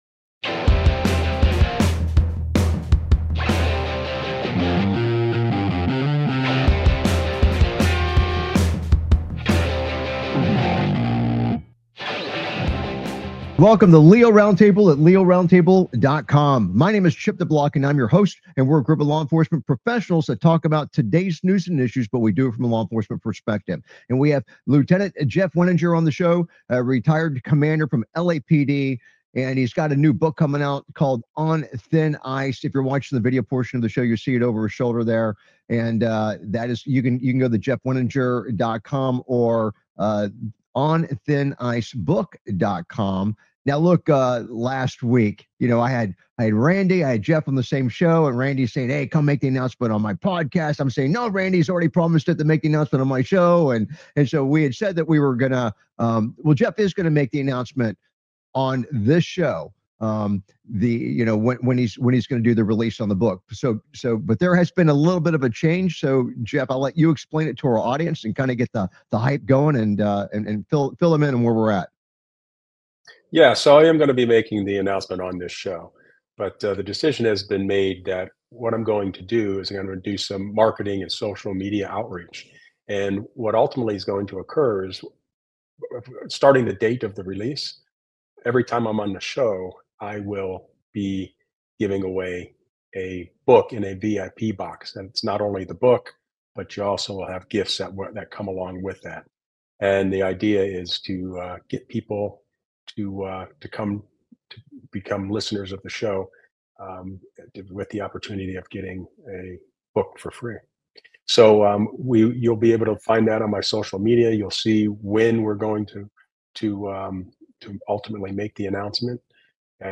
LEO Round Table is a nationally syndicated law enforcement satellite radio talk show discussing today's news and issues from a law enforcement perspective.
Their panelists are among a Who's Who of law enforcement professionals and attorneys from around the country.